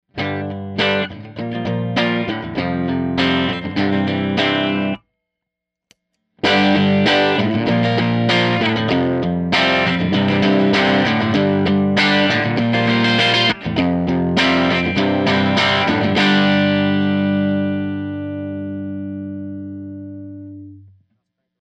here (a no-effect section followed by the boost-eq). recorded using a rickenbacker 330 w/flat wounds into the boost-eq (gain around 8 db, eq flat) into a fender '57 bassman ltd reissue, tracked with coincident sm57's into an api 3124+ mic pre into an alesis hd24xr. the sample shows how the boost-eq pushes the bassman into a beautifully complex overdrive tone.